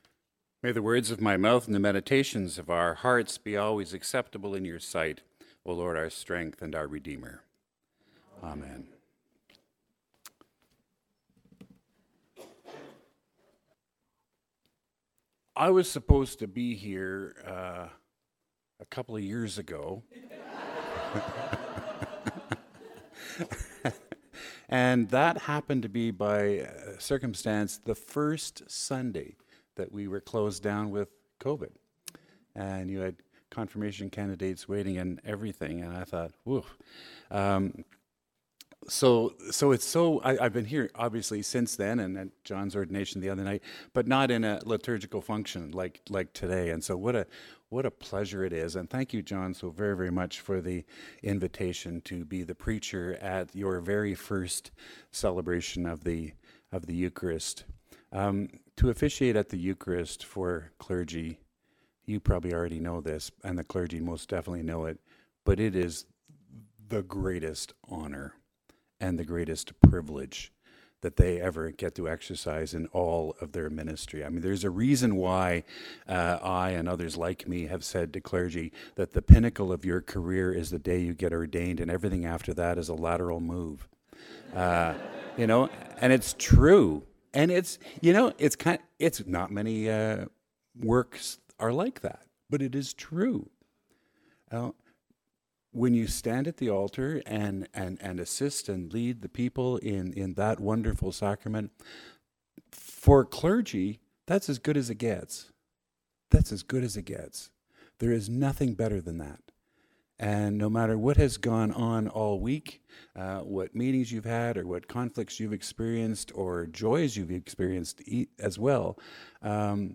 Gathered at the Table. A sermon in thanks for the gift of Holy Eucharist.